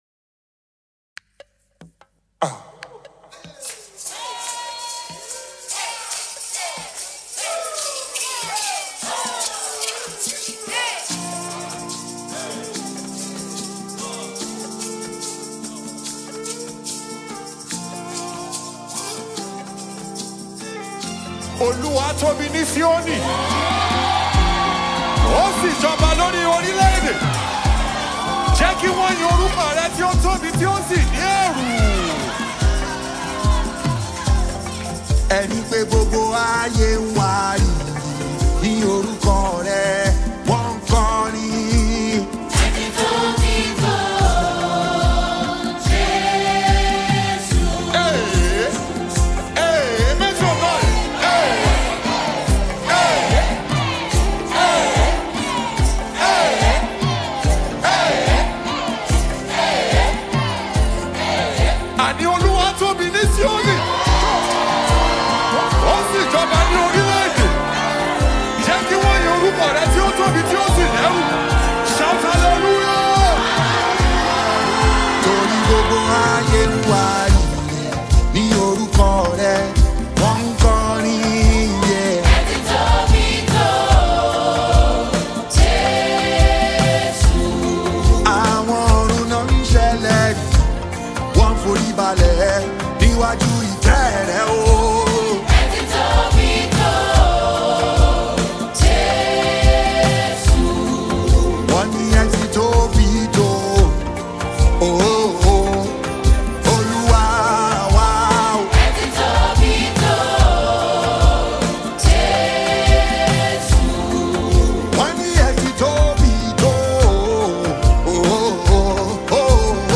is an uplifting gospel anthem
soulful vocals bring depth and emotion
complementing the song’s rich and vibrant production.